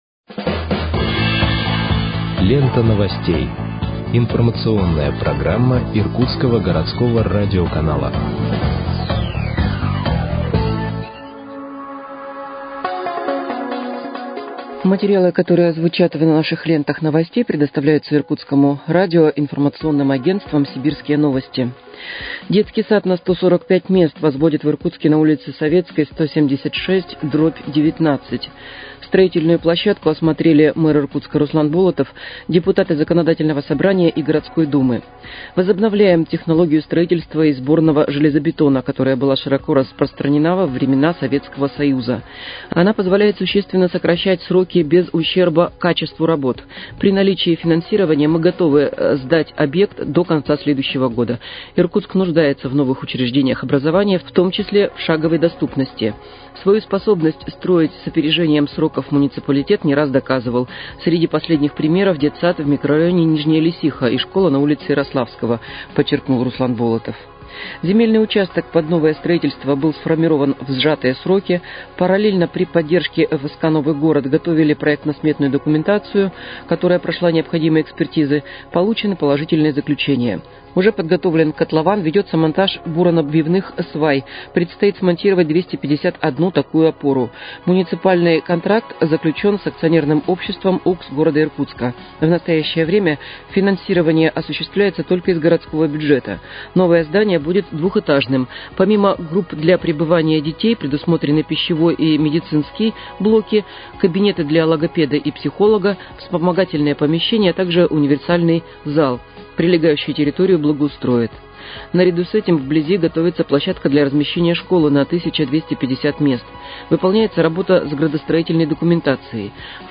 Выпуск новостей в подкастах газеты «Иркутск» от 30.10.2025 № 1